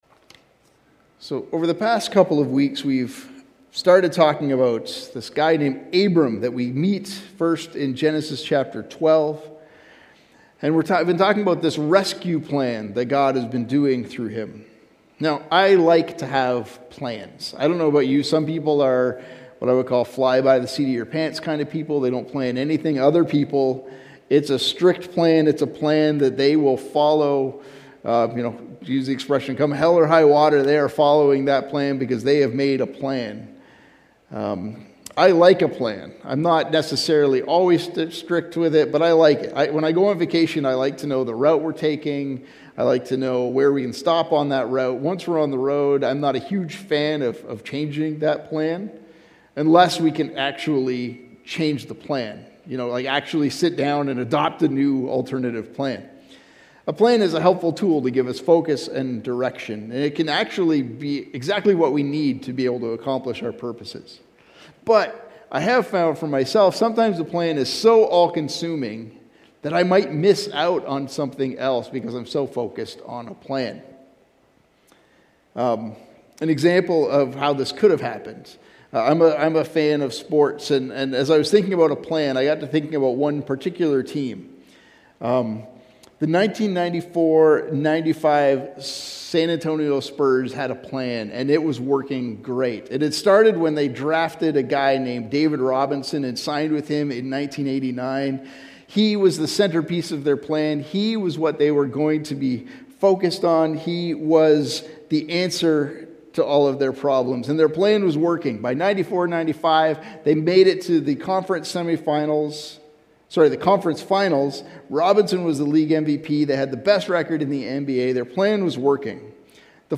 Sermons | Covenant Christian Community Church
We are so glad to have you with us for our Sunday Service.